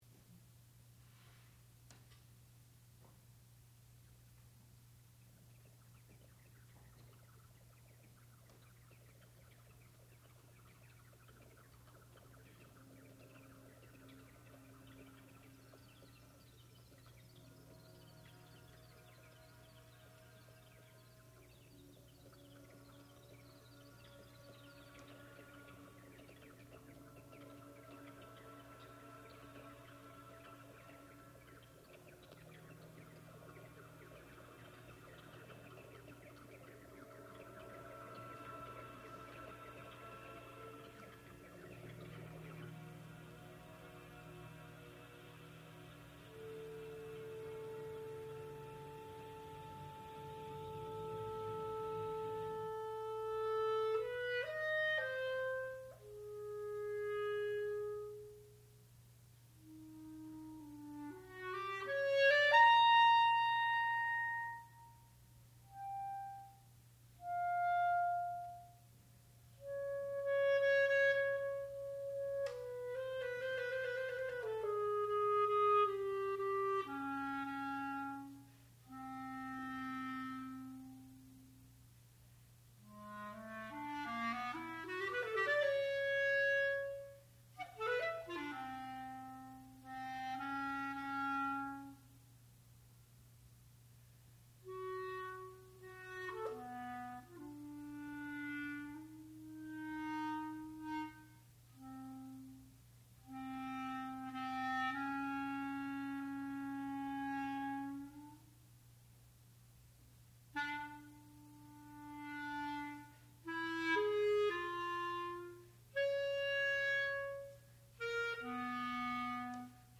Urbana, for clarinet and tape
sound recording-musical
classical music
clarinet
Master's Recital